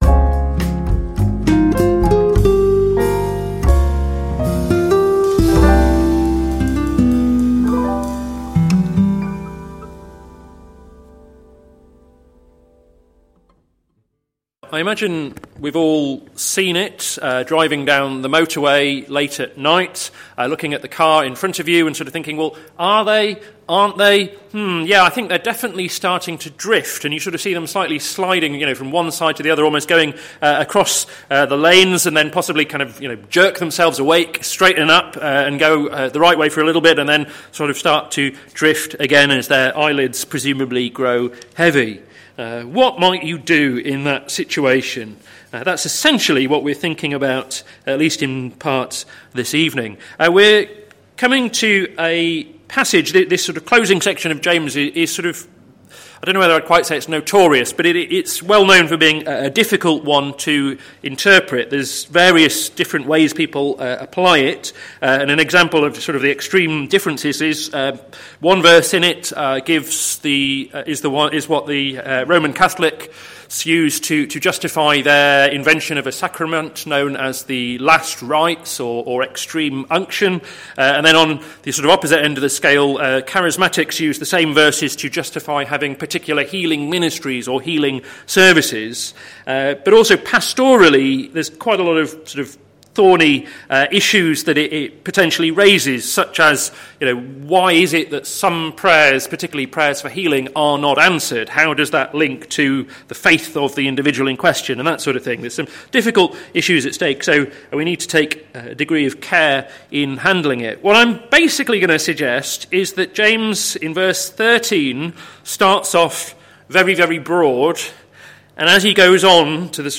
Sermon Series - Caught in Two Minds - plfc (Pound Lane Free Church, Isleham, Cambridgeshire)